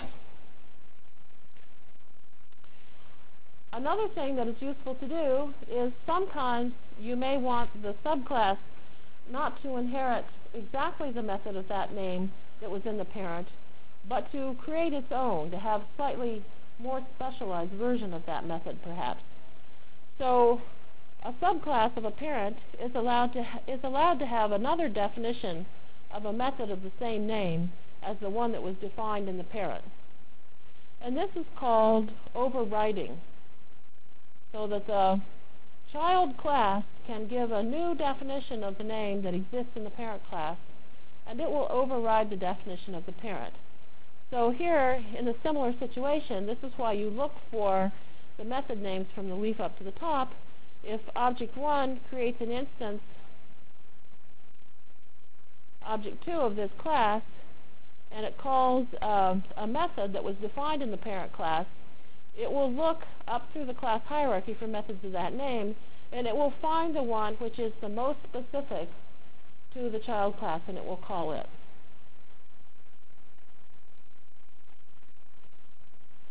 From Jan 27 Delivered Lecture for Course CPS616 -- Java Lecture 2 -- Basic Applets and Objects CPS616 spring 1997 -- Jan 27 1997.